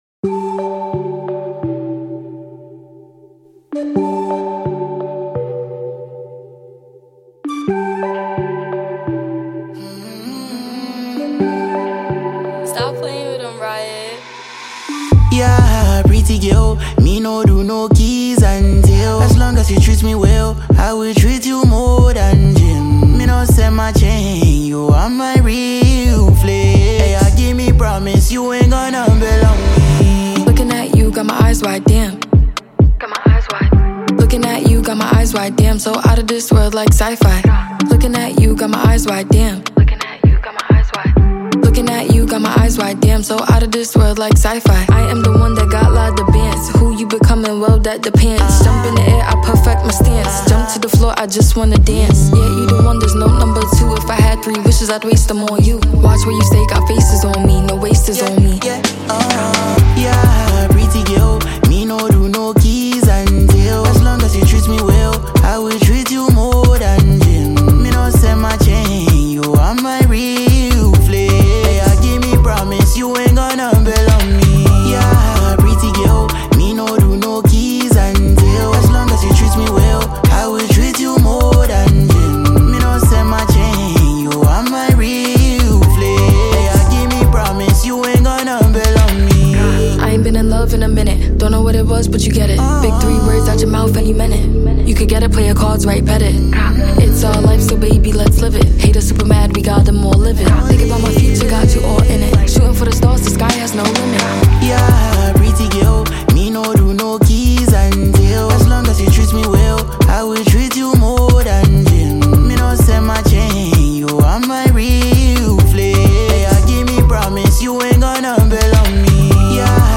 American super-talented rapper and songwriter